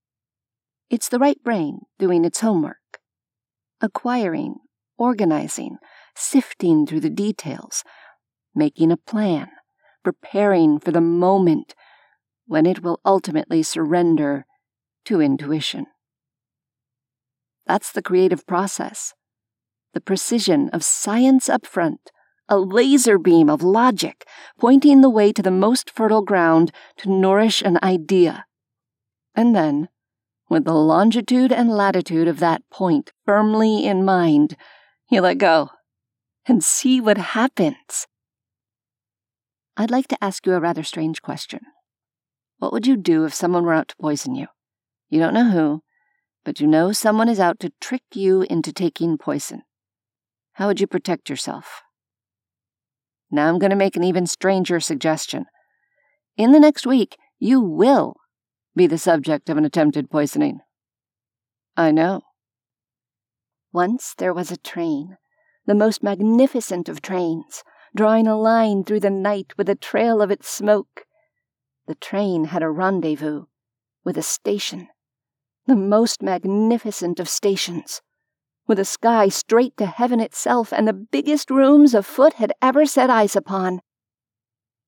Corporate Reel